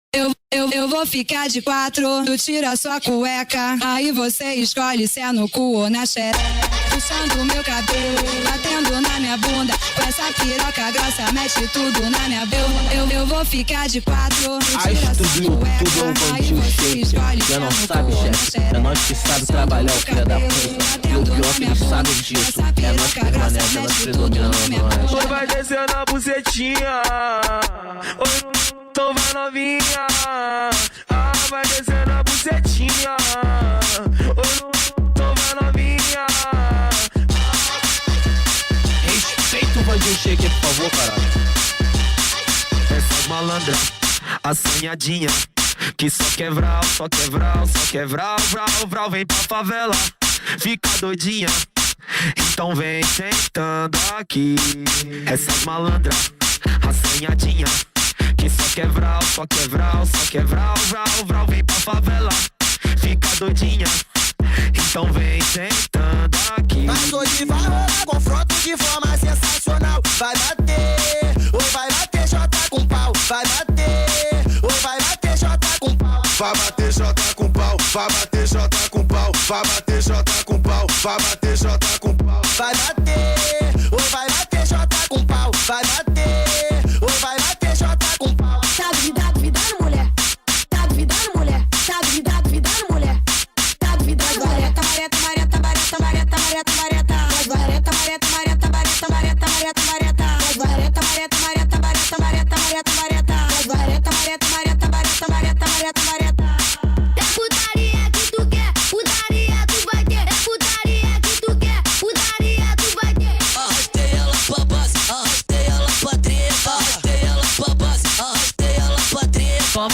2024-11-12 23:13:55 Gênero: Phonk Views